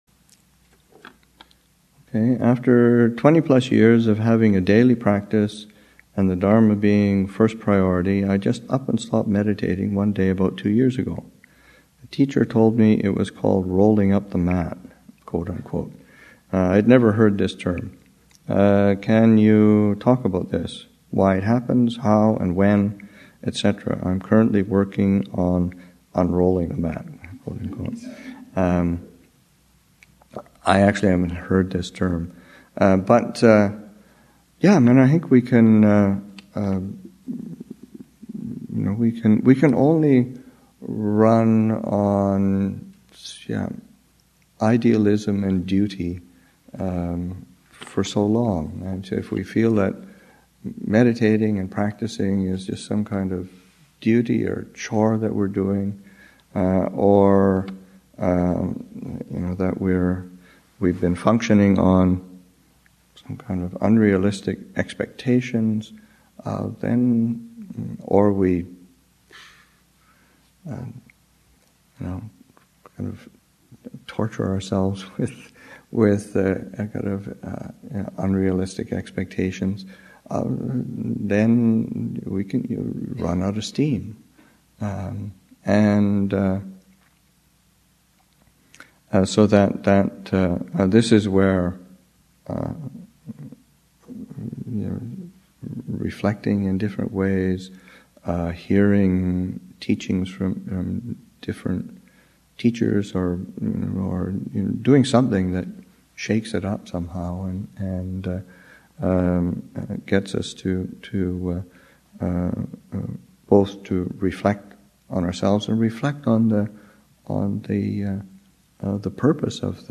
Abhayagiri Monastic Retreat 2013, Session 7 – Nov. 29, 2013